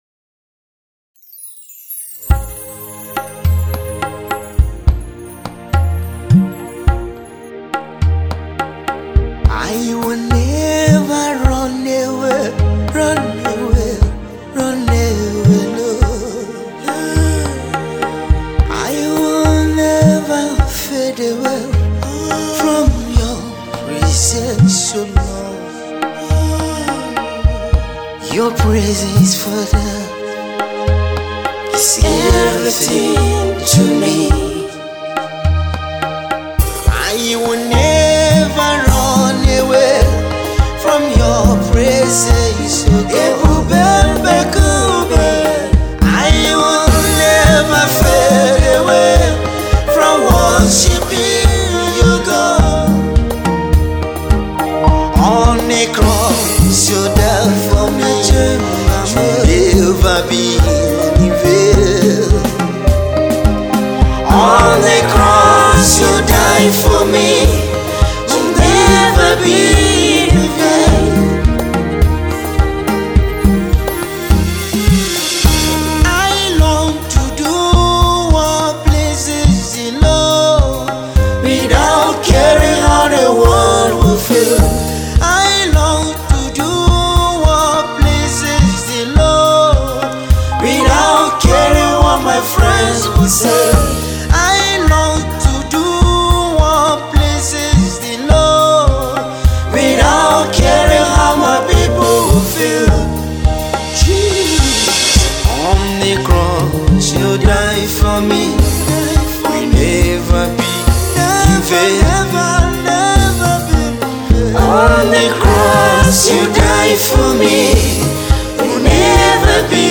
gospel singer
contemporary Christian music